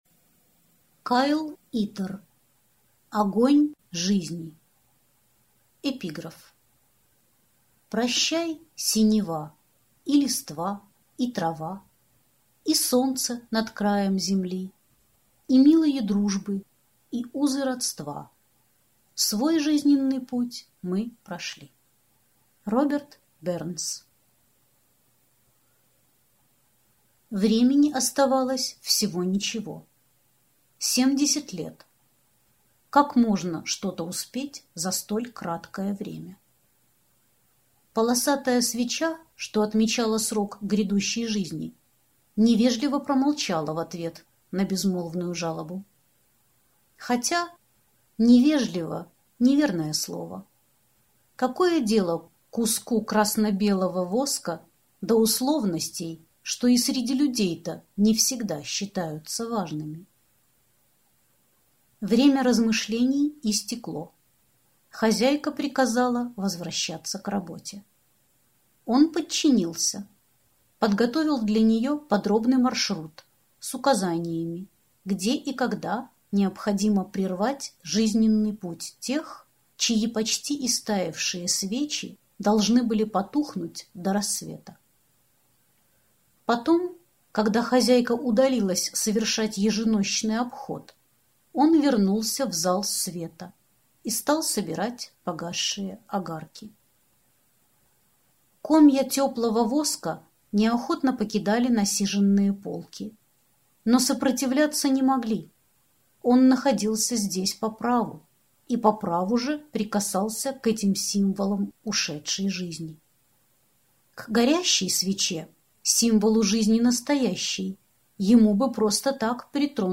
Аудиокнига Огонь жизни | Библиотека аудиокниг